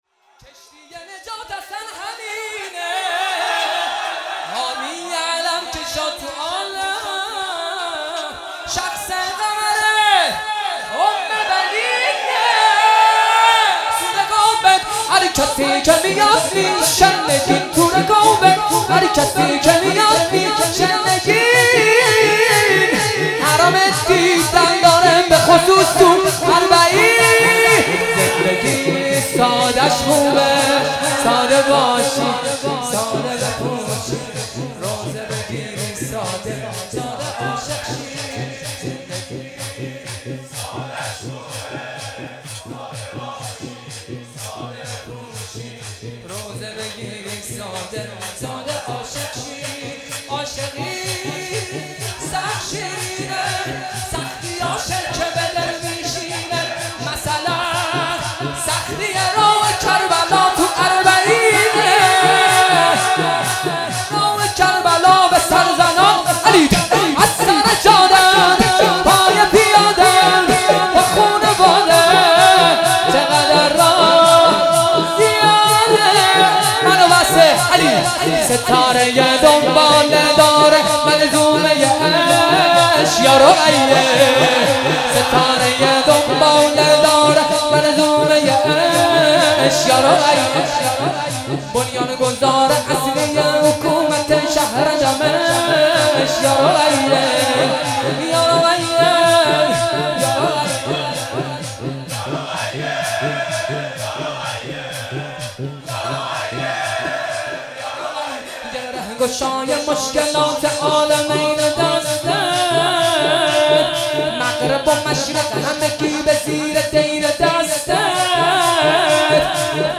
مراسم شب ۲ صفر ۱۳۹۷
دانلود شور